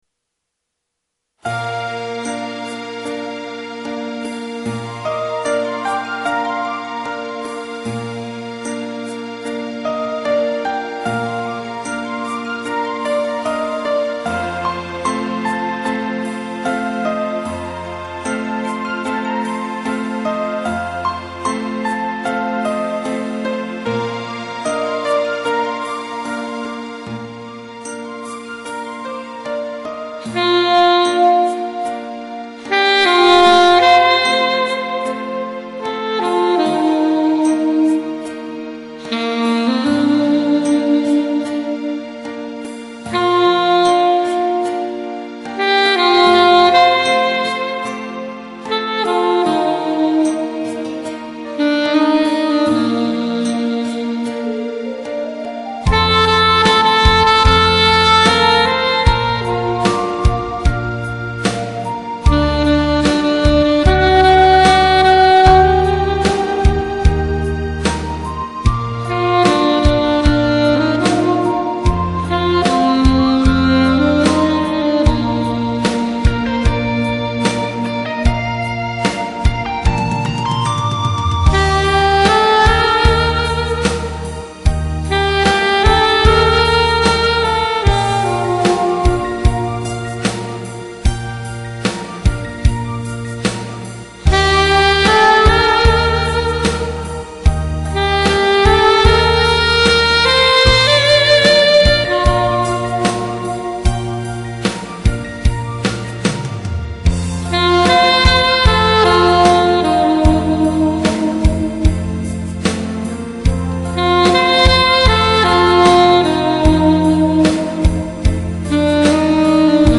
악기연주곡